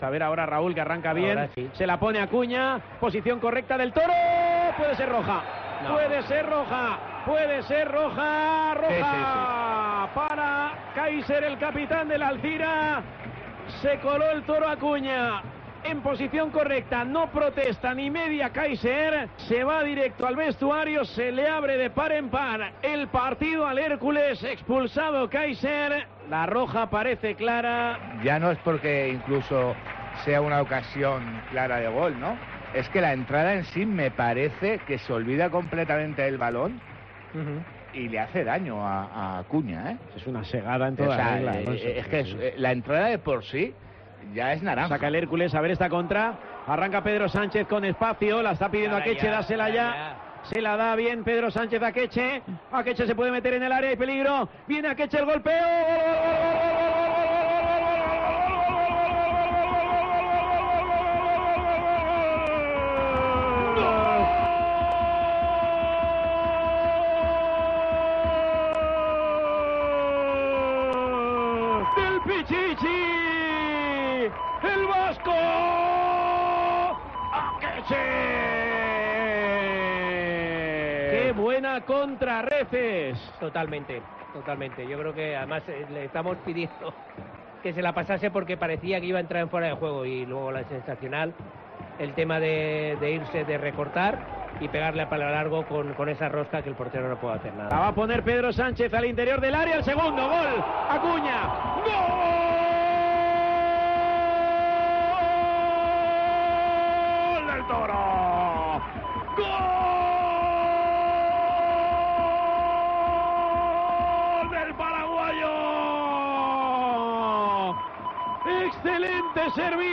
Así sonó la victoria del Hércules ante el Alzira en Tiempo de Juego Alicante